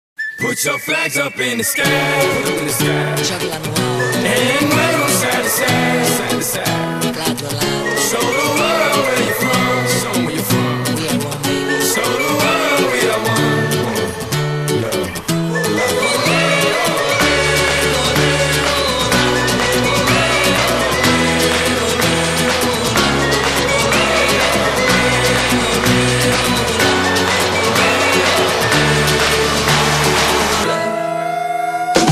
M4R铃声, MP3铃声, 欧美歌曲 127 首发日期：2018-05-15 21:56 星期二